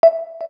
KEYTONE1_3.wav